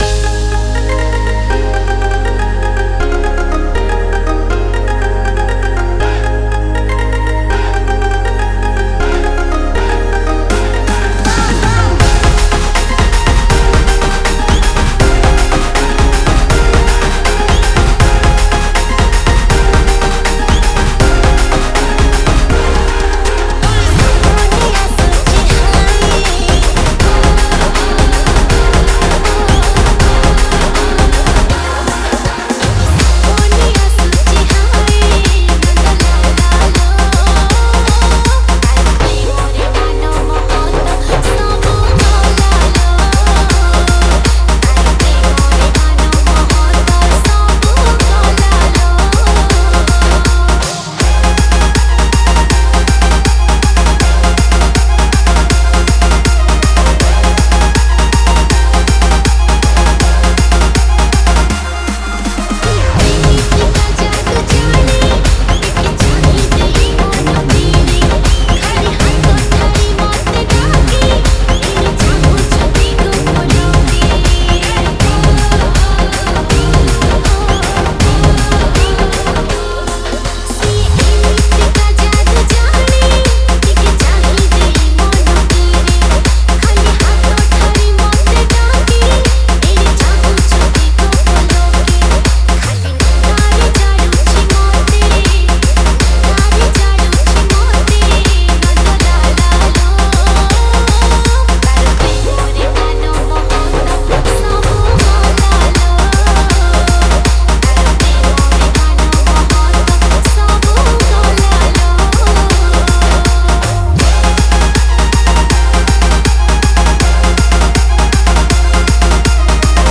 Bhajan Dj Remix